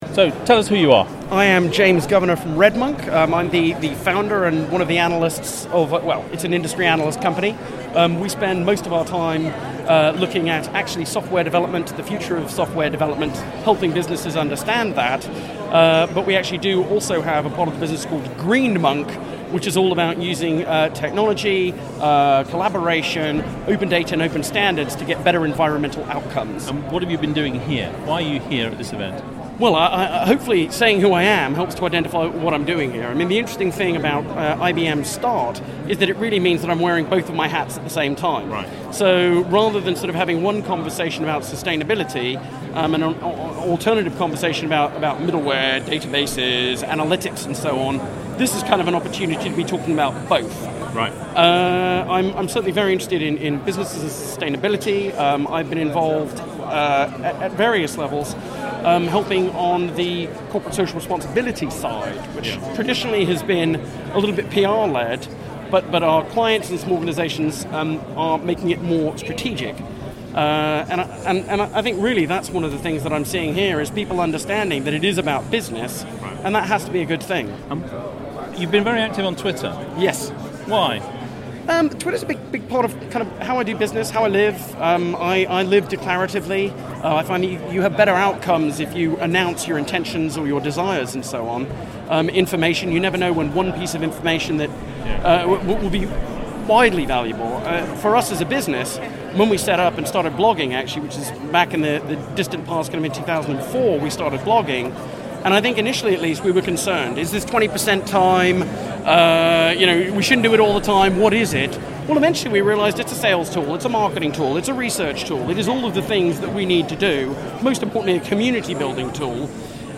IBM Start Day 8 - A Conversation